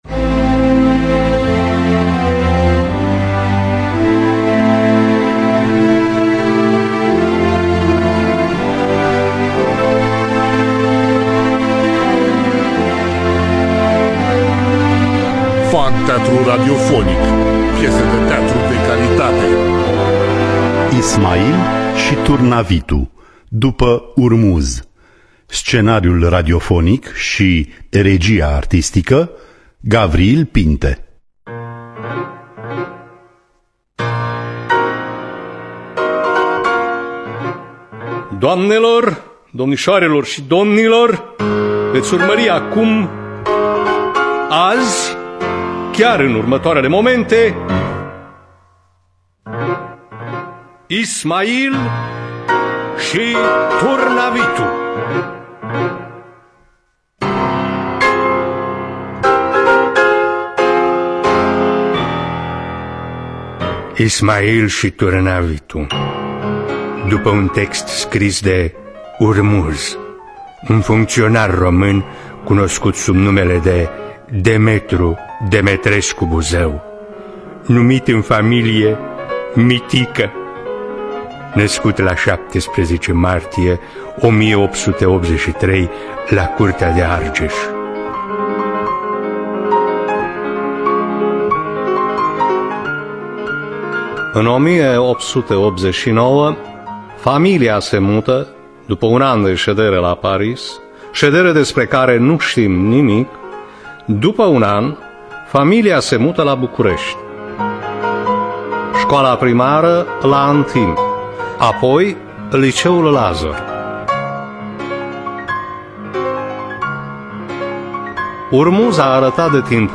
Ismail și Turnavitu de Urmuz – Teatru Radiofonic Online